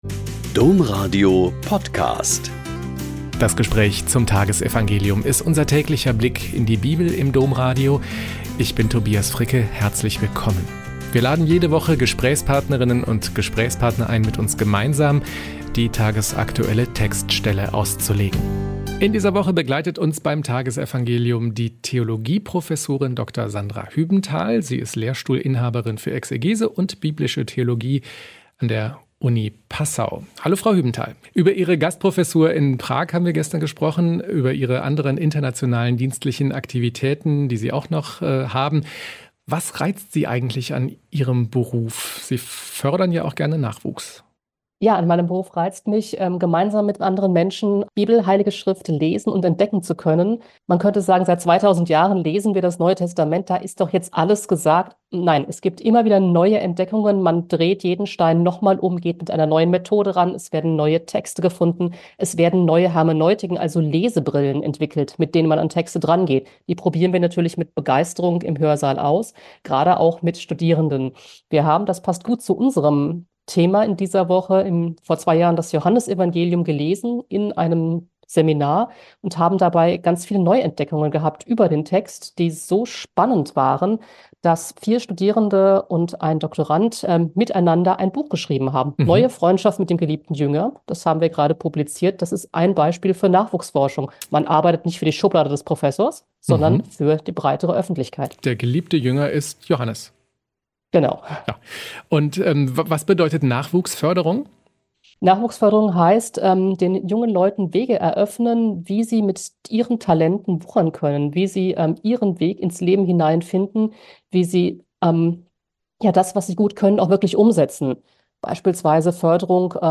Joh 3,16-21 - Gespräch